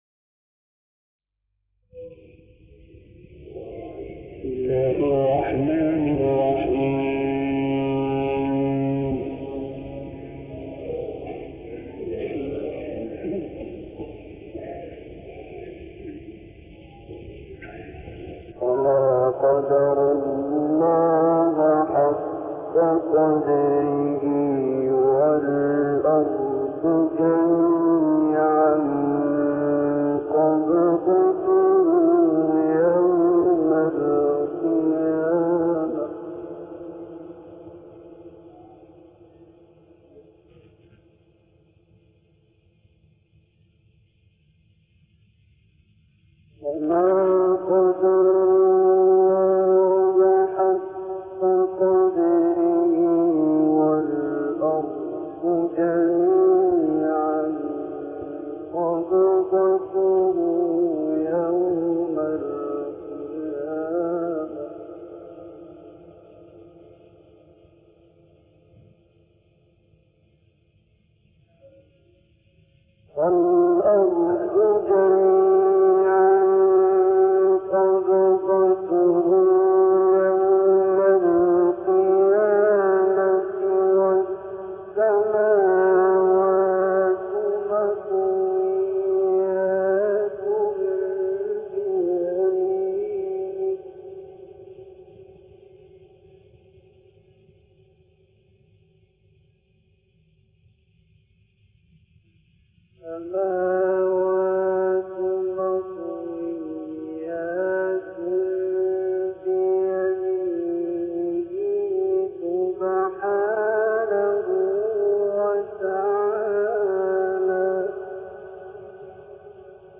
حفلات نادرة جدا للشيخ عبد الباسط عبد الصمد النادرة من المسجد الأقصى